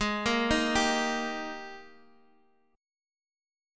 G#M7sus2 Chord